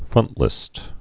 (frŭntlĭst)